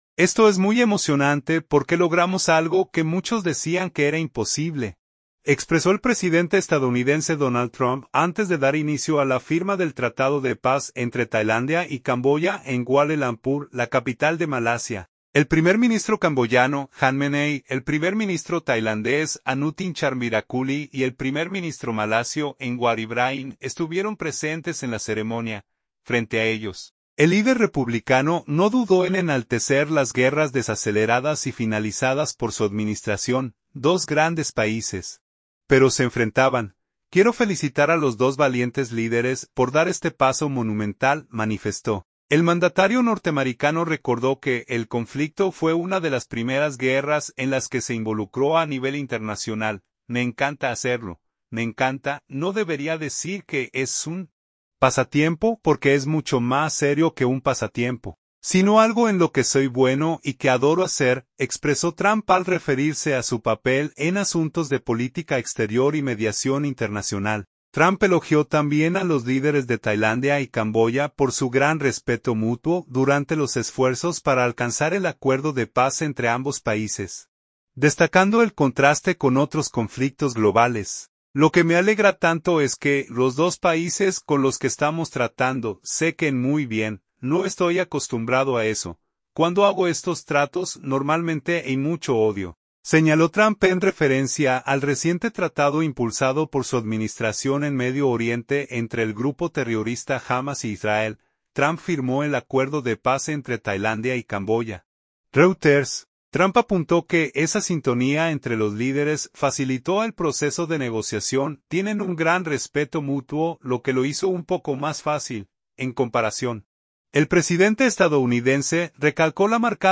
El presidente de Estados Unidos brindó un discurso desde Kuala Lumpur y enalteció su figura como pacificador.